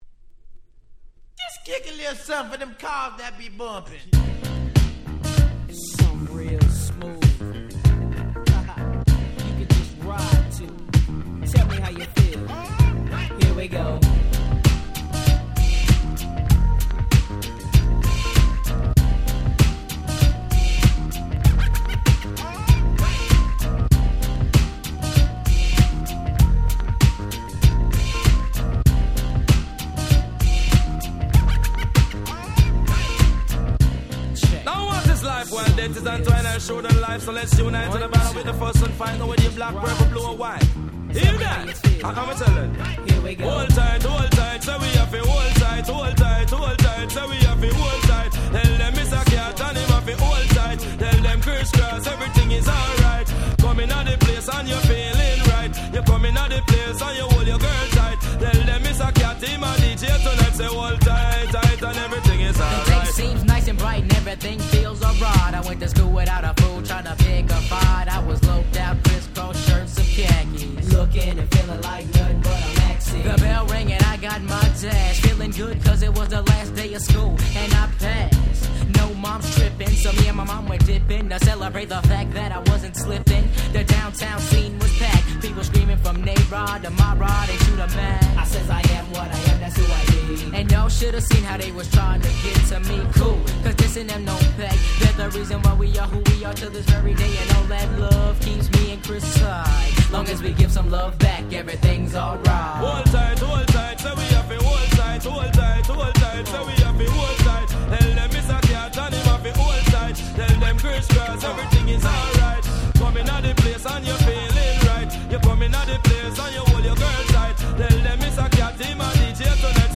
93' Super Hit Hip Hop !!